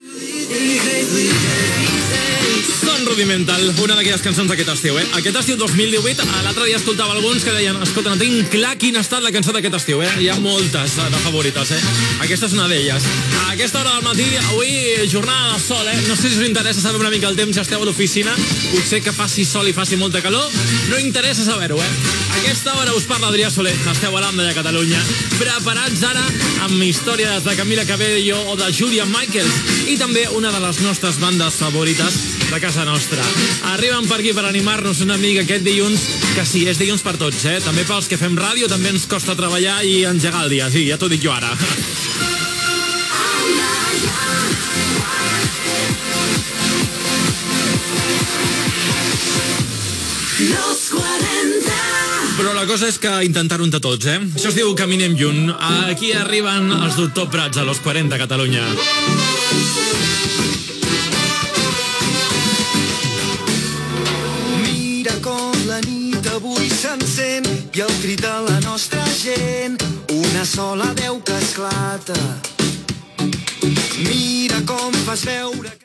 Tema musical, estat del temps, propers temes musicals, indicatiu del programa, identificació de l'emissora i tema musical
Musical